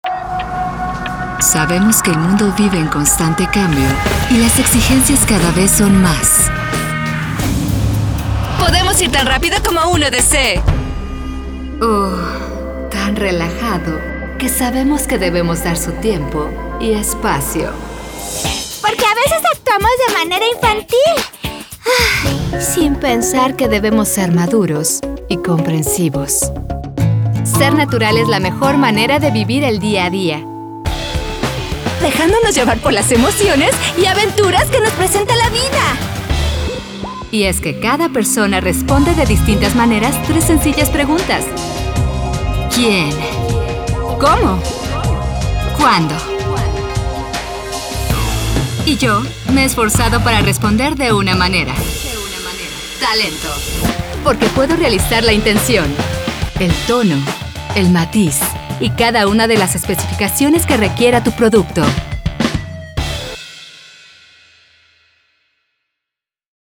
西班牙语女声
低沉|激情激昂|大气浑厚磁性|沉稳|娓娓道来|科技感|积极向上|时尚活力|神秘性感|调性走心|亲切甜美|素人